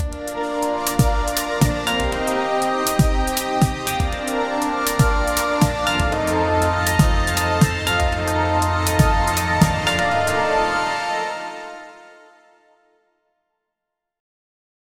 epic soundtrack to anime opening harmonious musical beautiful without words with chinese notes fast rnb positive 120bpm kind melodic
epic-soundtrack-to-anime--hs4y5yjt.wav